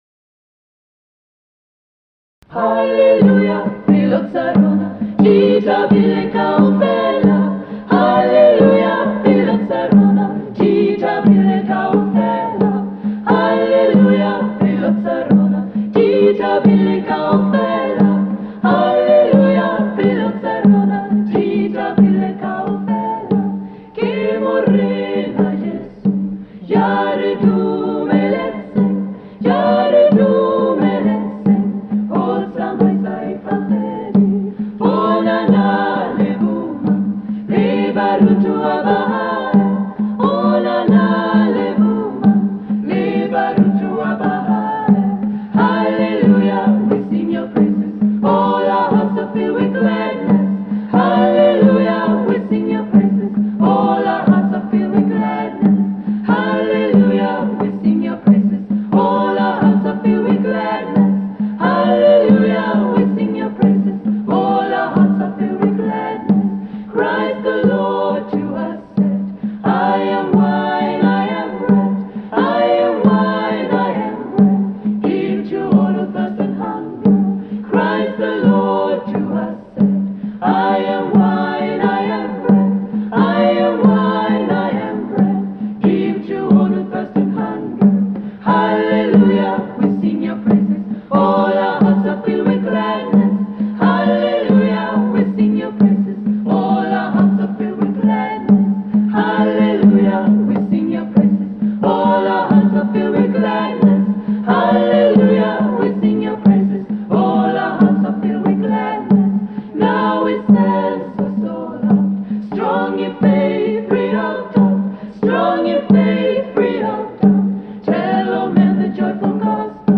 Voicing: Three-Part Treble